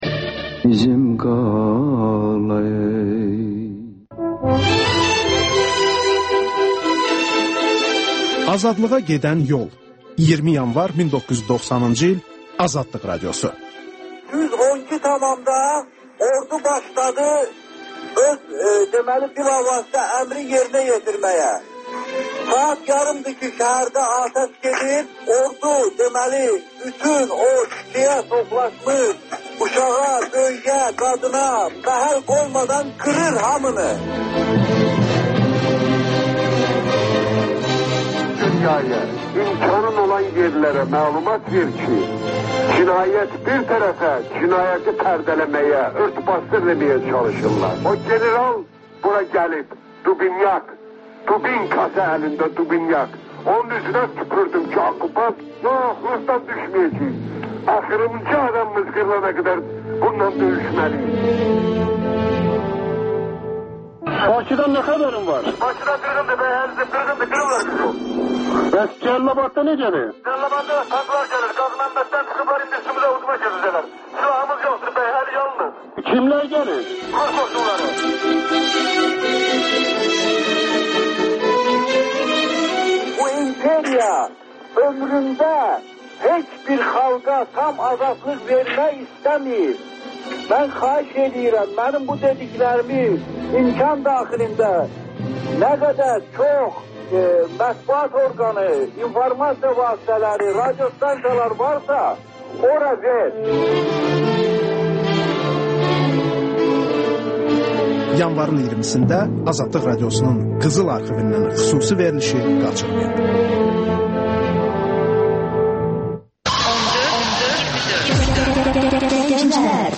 Ölkənin tanınmış simaları ilə söhbət (Təkrar)